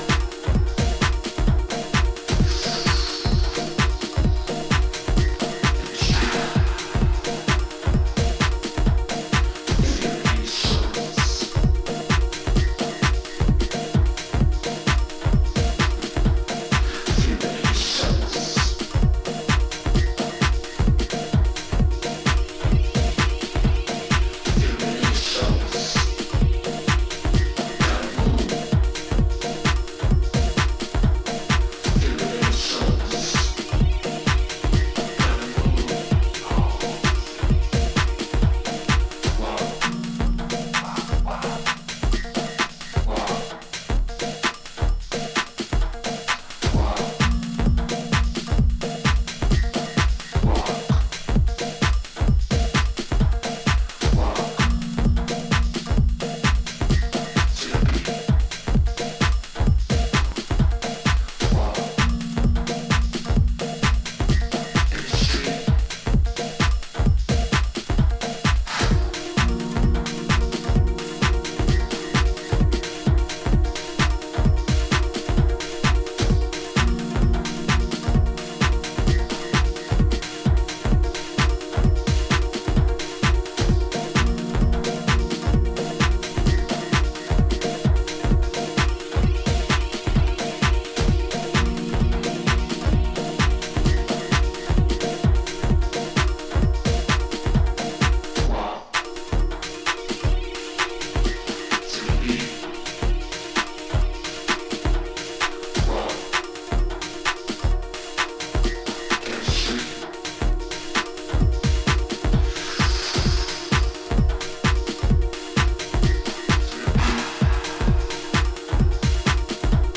rawer house jam
Deep house
House